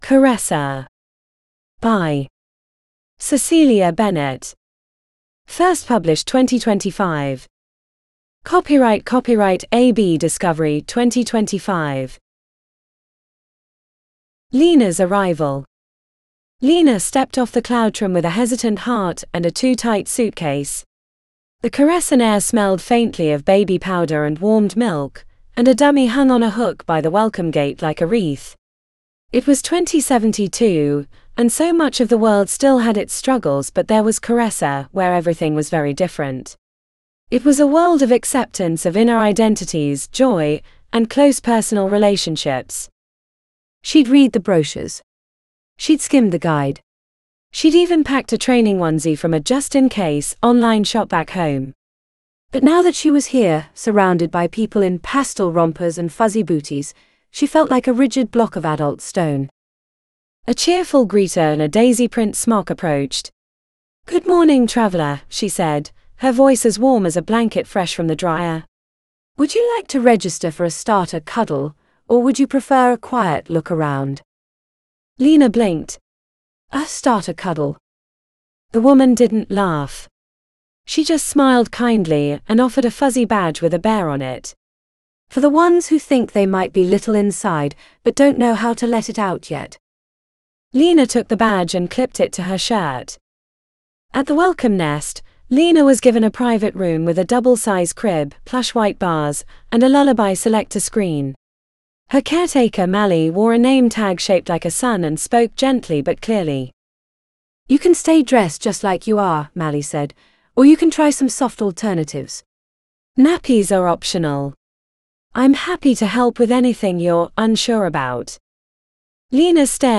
Caressa (AUDIOBOOK): $US5.75